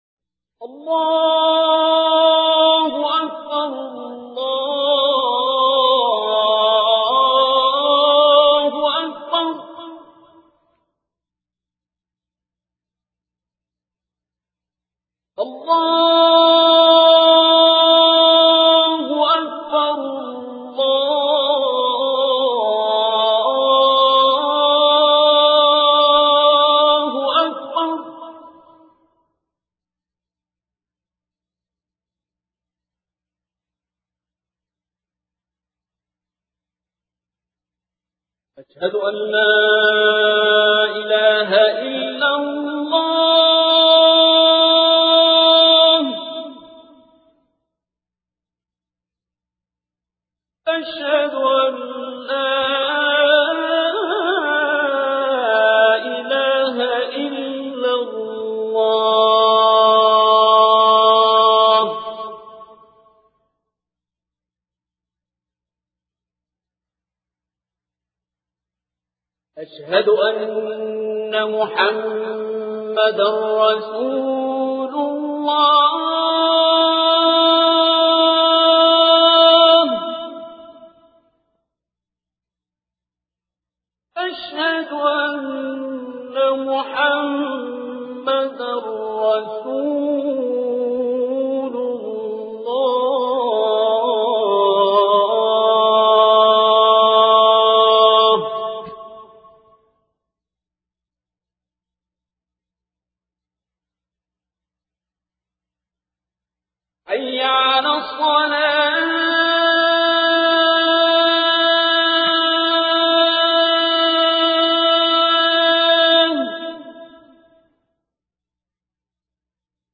عنوان المادة أذان بصوت الشيخ عبدالباسط -2